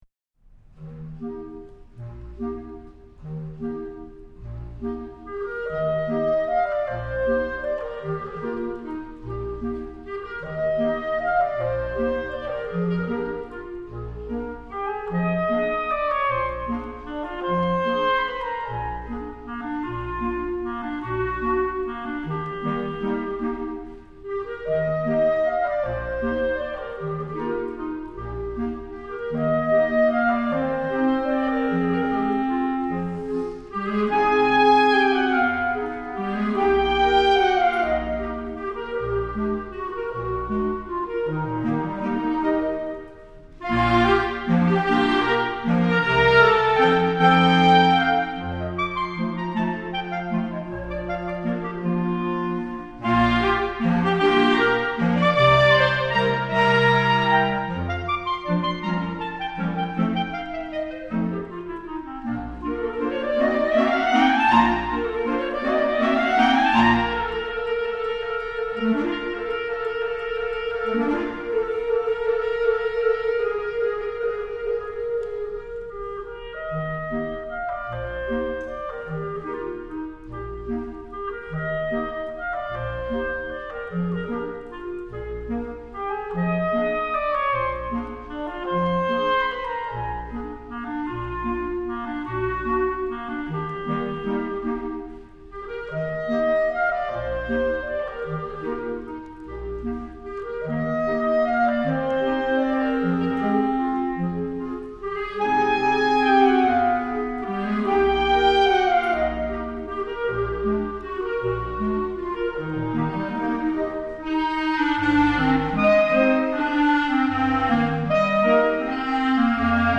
クラリネット７重奏版
E-flat Major
（原調）